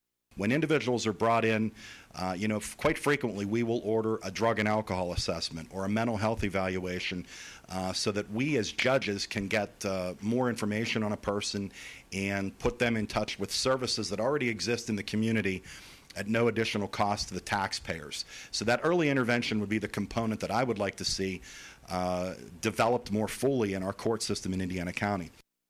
The three candidates for Indiana County Court of Common Pleas met at Renda Broadcasting and Digital’s Studios this morning to discuss several issues related to their position.